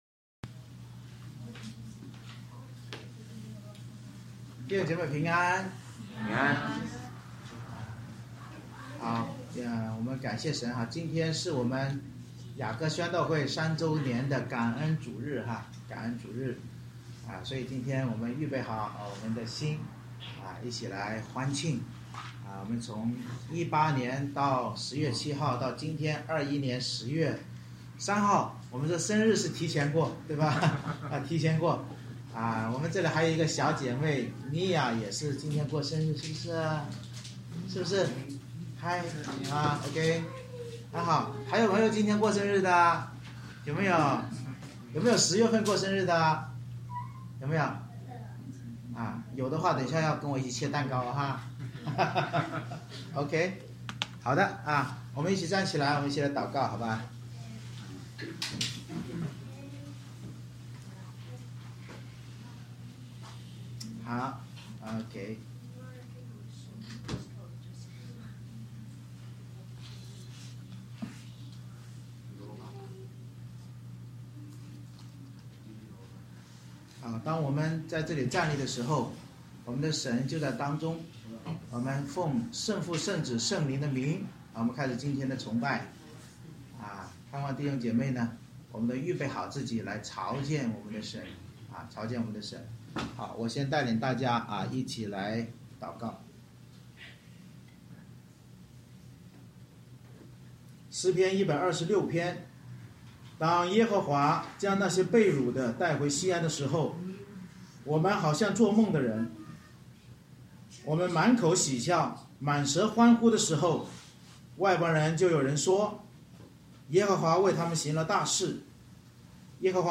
2021年10月03日牧祷与诗歌敬拜（教会年庆）